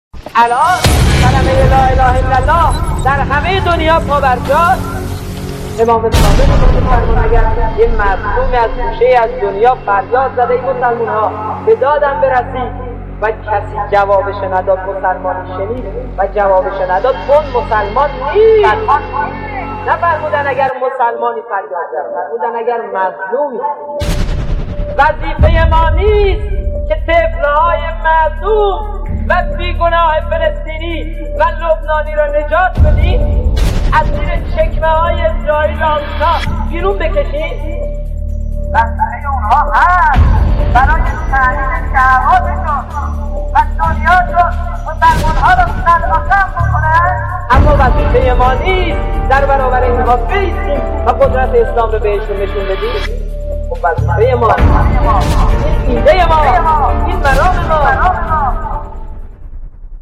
در حین عملیات رمضان در تاریخ بیست‌وهفتم تیرماه سال ۱۳۶۱ قرارگاه فتح